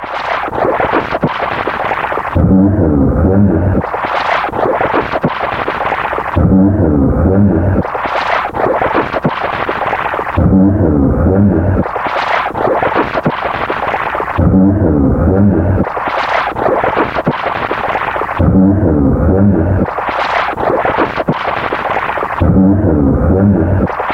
卷到卷的磁带操纵/噪音循环 " 泡沫的威胁
描述：一个音调低下、扭曲的声音与水一样的物理磁带操作交替出现，记录在1/4"磁带上，并进行物理循环
Tag: 水样便 投向下 磁带操纵 语音 胶带环